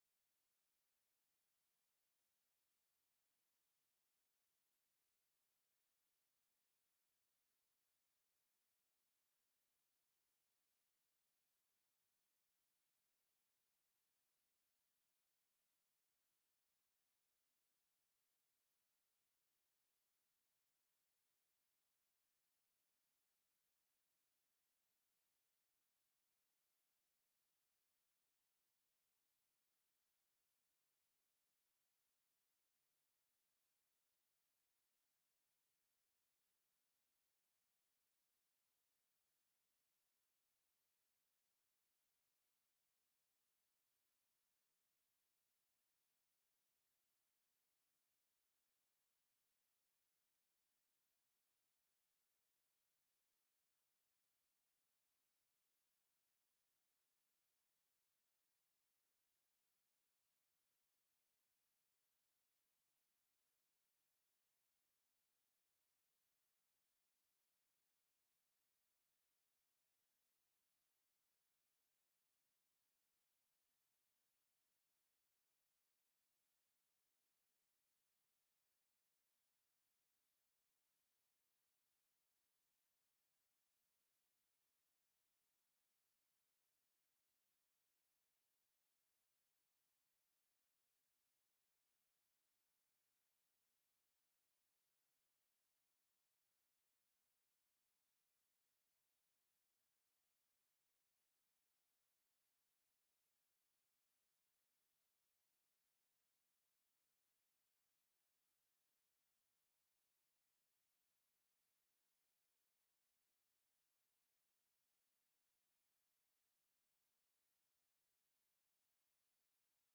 Commissie Burger en bestuur 10 december 2024 19:30:00, Gemeente Ouder-Amstel
Download de volledige audio van deze vergadering